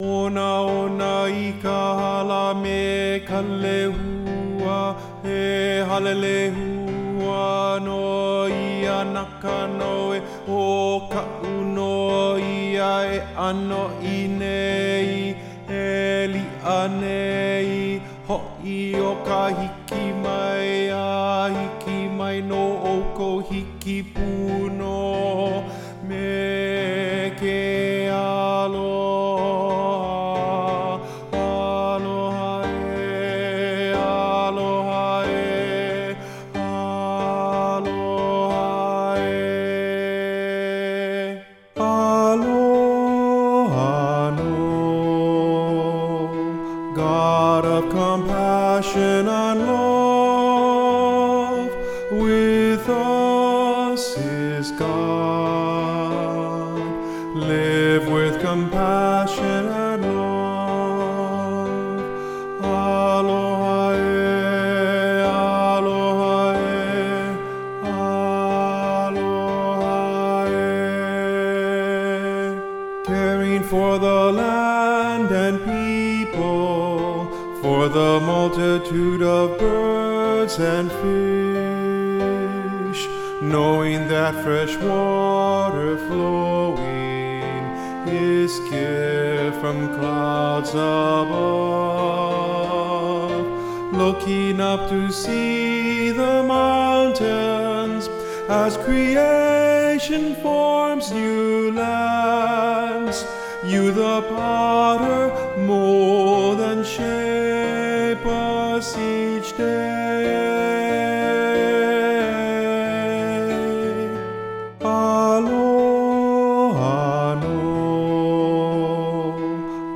Soprano   Instrumental | Downloadable   Voice | Downloadable